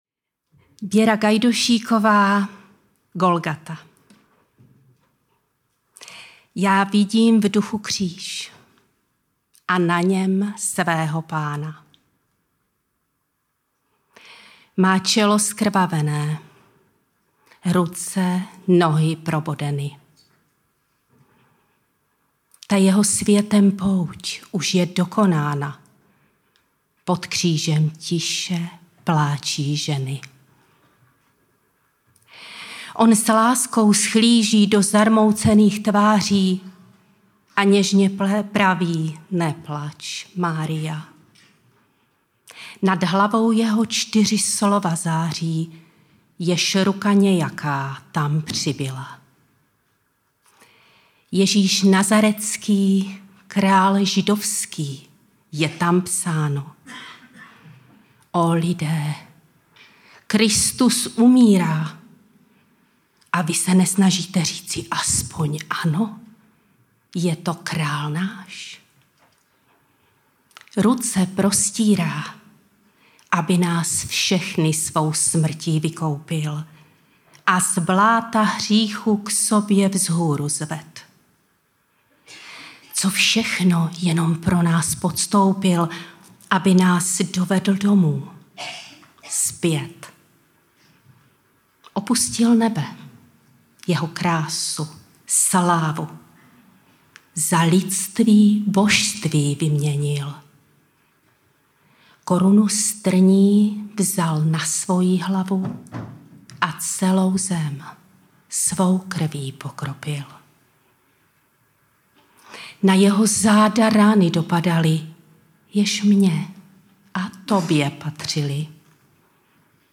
Záznamy z konference
báseň Golgata